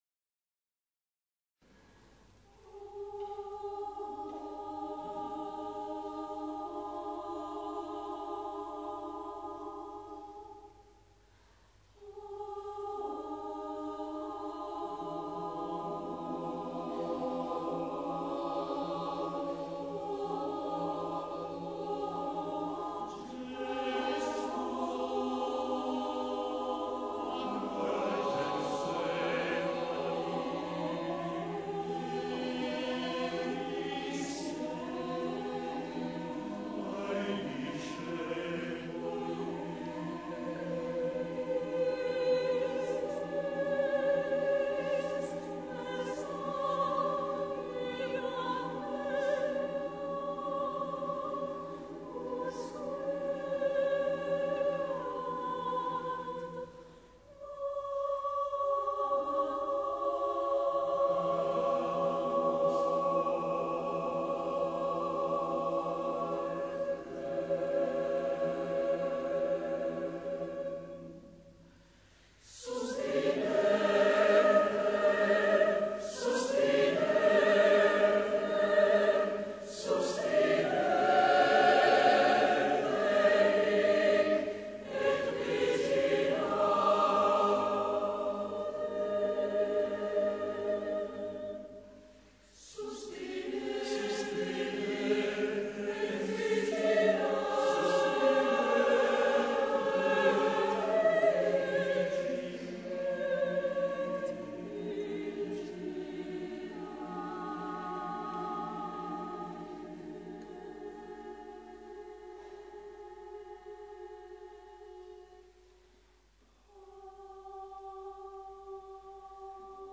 · Contenuto in Choir-Sacred a Cappella